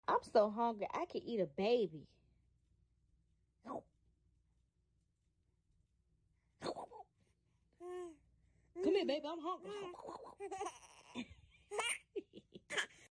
But jokes aside, the baby sounds in this sound is so cute :D!